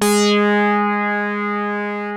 OSCAR 8 G#4.wav